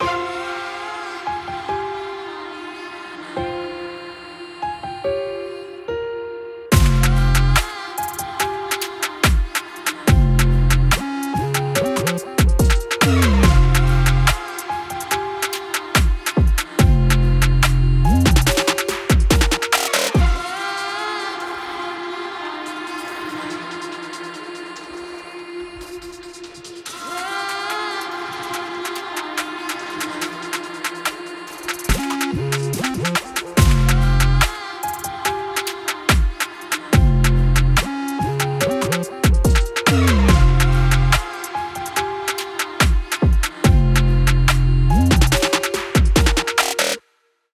(Drill)